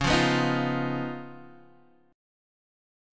C# 9th